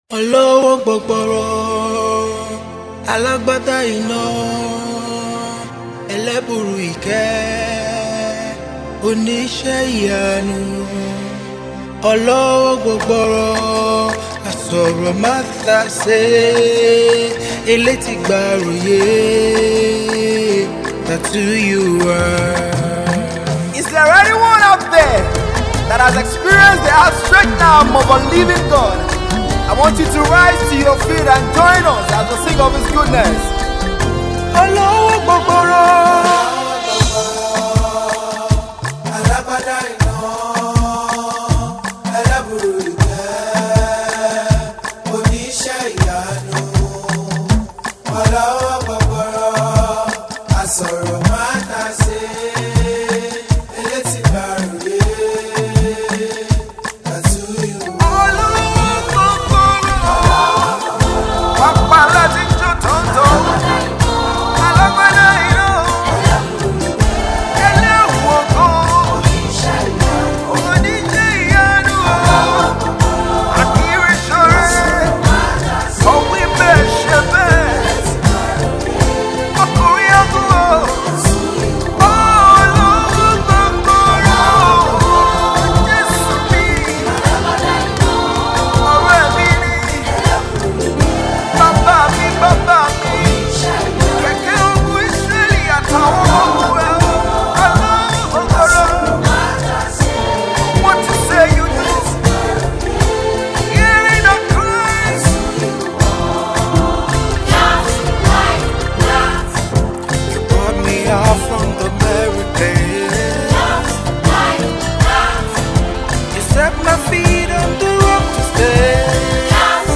highlife song